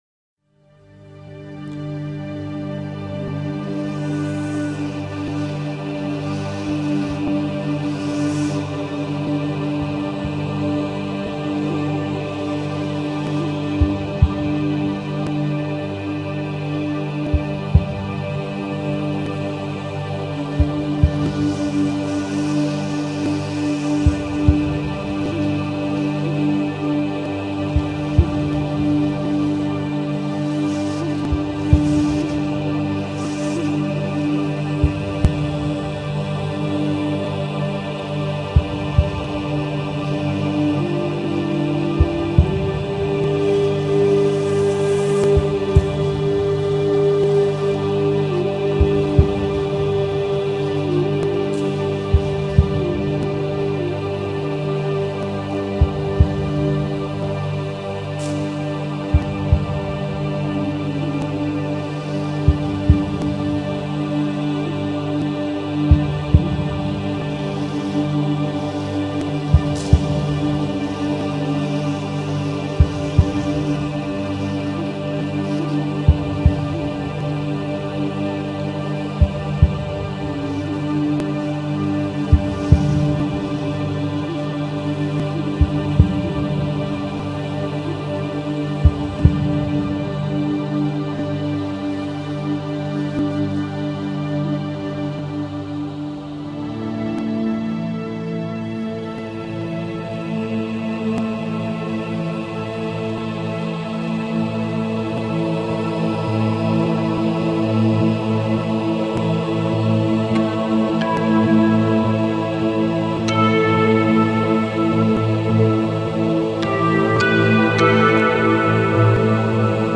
m�ditation